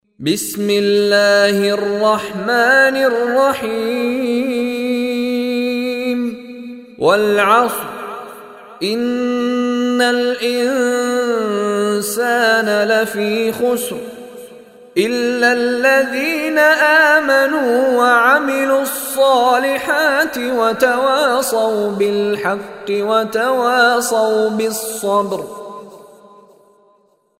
Surah Al Asr, listen online mp3 Arabic recitation, recited by Mishary Rashid Alafasy.